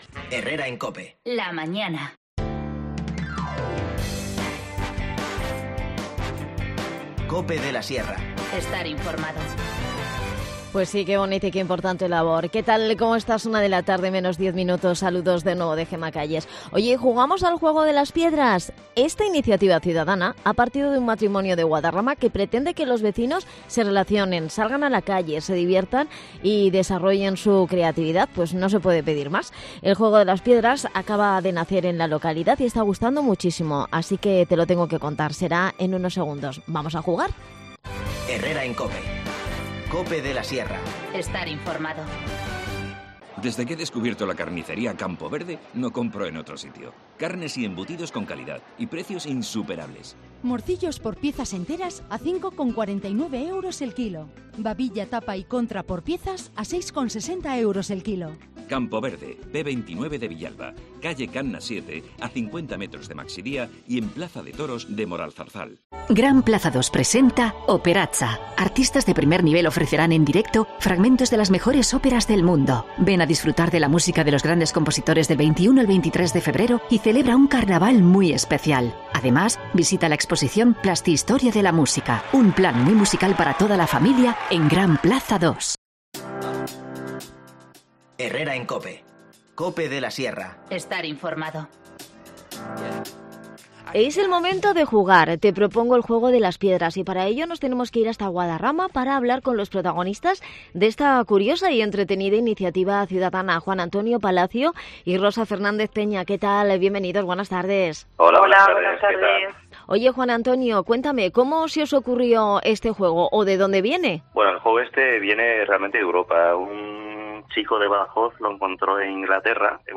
El juego de Las Piedras es una iniciativa ciudadana que ha nacido en Guadarrama para que los vecinos se relacionen, diviertan y desarrollen su imaginación y creatividad. Hablamos con los protagonistas de esta actividad